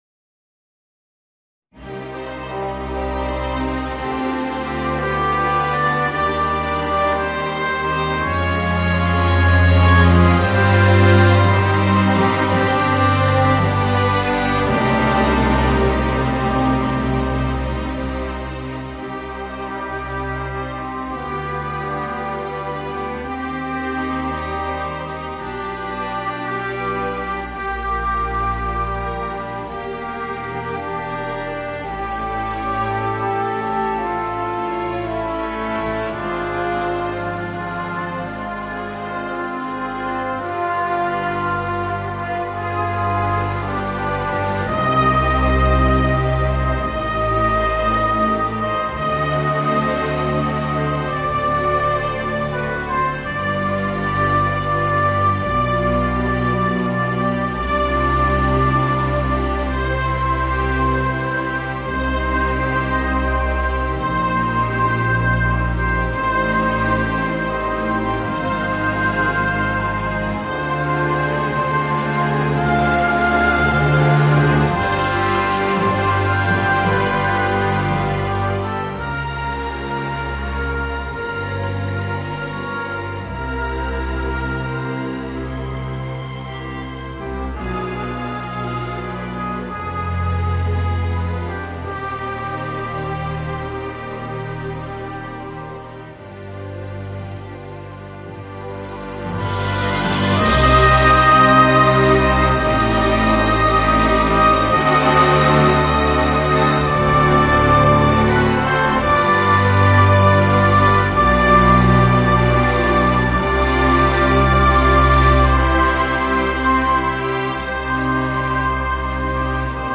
Voicing: Violoncello and Orchestra